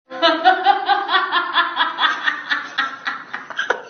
SFX哈哈哈哈哈的笑音效下载
SFX音效